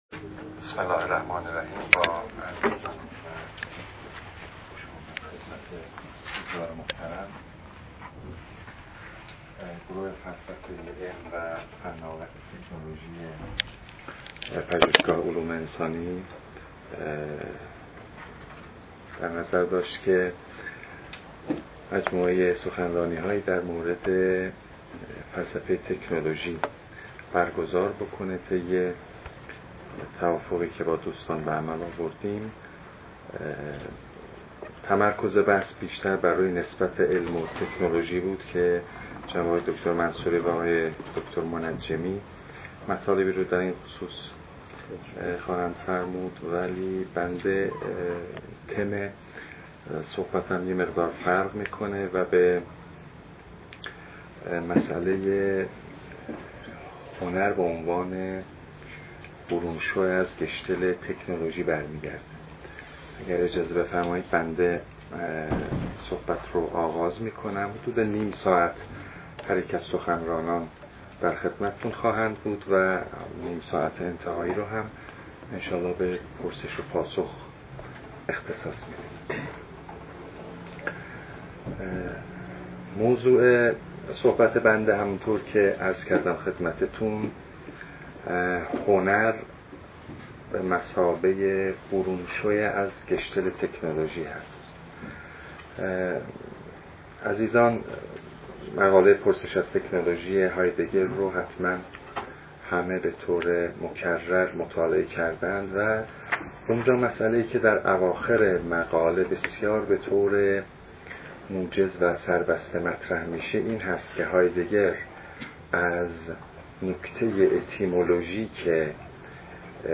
فرهنگ امروز: فایل حاضر سخنرانی